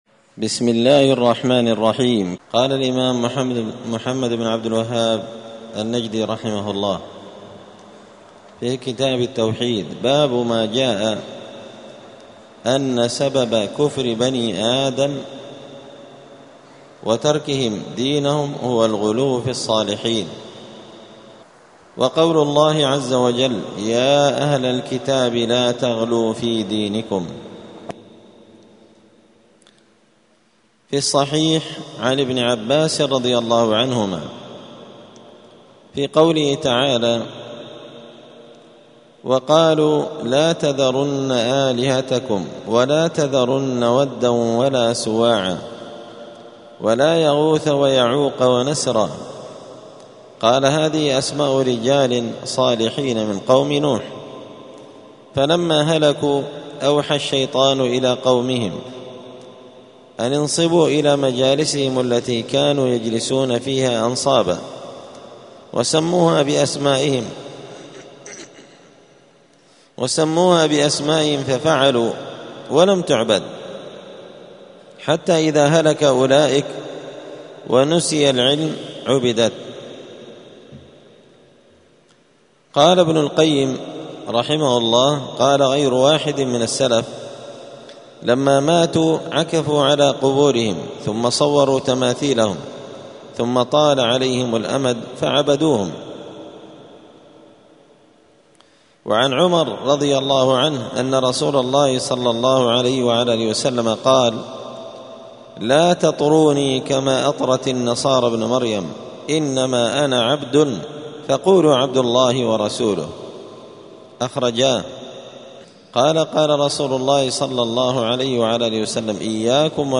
دار الحديث السلفية بمسجد الفرقان بقشن المهرة اليمن
*الدرس الرابع والخمسون (54) {الباب التاسع عشر باب ما جاء أن سبب كفر بني آدم وتركهم دينهم هو الغلو في الصالحين}*